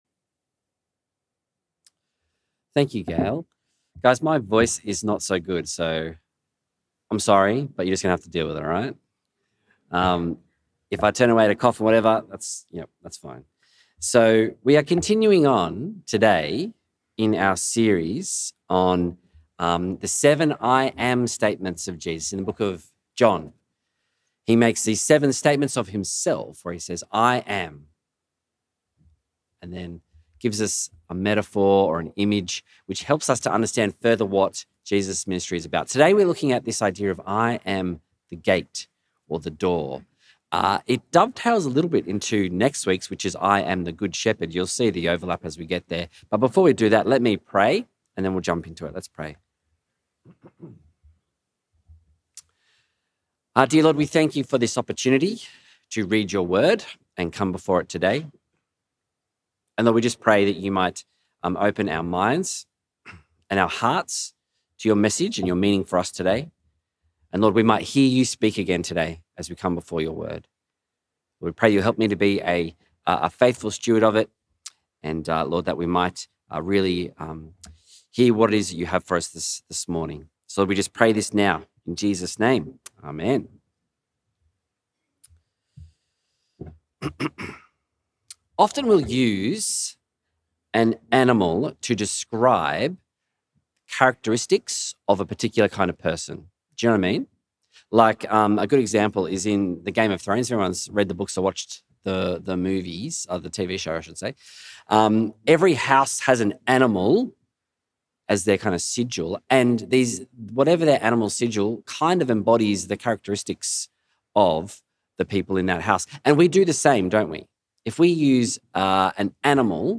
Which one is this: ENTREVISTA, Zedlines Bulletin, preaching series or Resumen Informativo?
preaching series